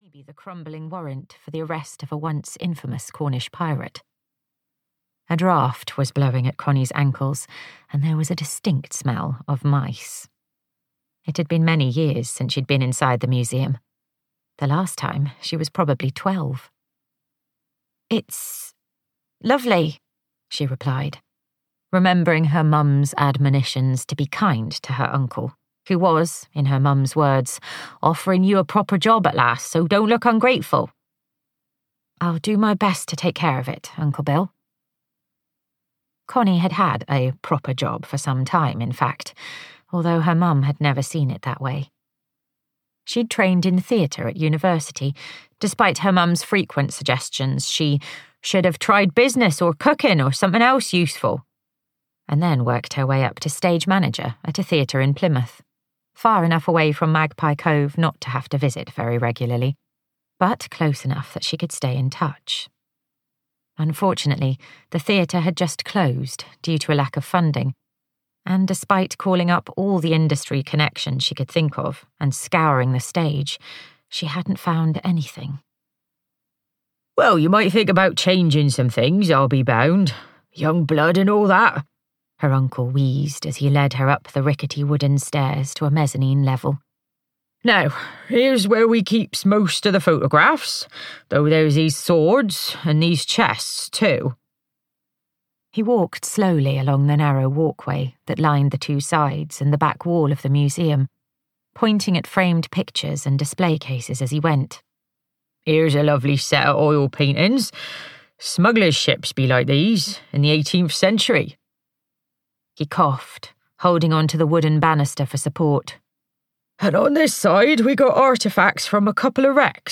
Audiobook Daughters of Magpie Cove written by Kennedy Kerr.
Ukázka z knihy